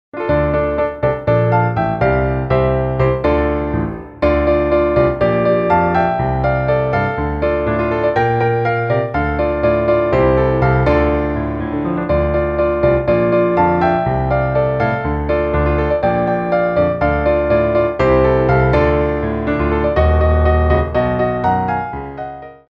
4/4 (16x8)